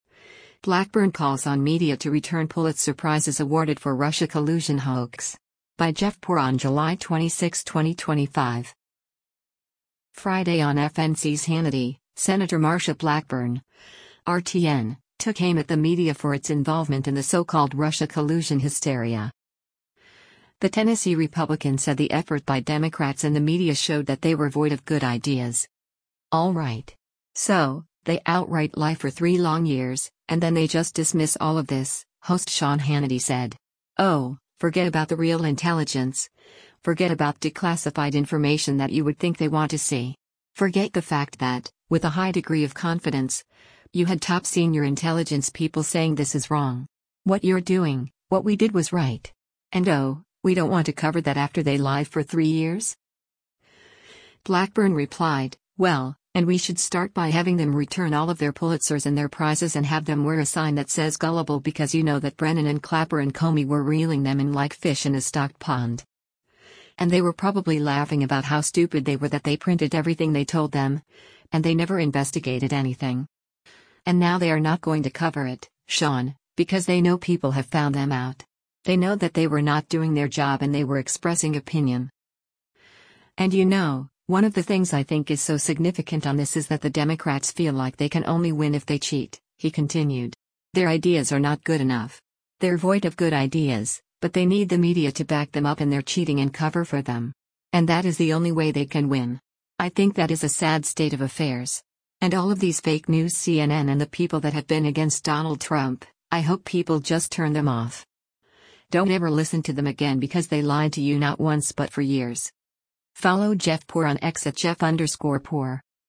Friday on FNC’s “Hannity,” Sen. Marsha Blackburn (R-TN) took aim at the media for its involvement in the so-called Russia collusion hysteria.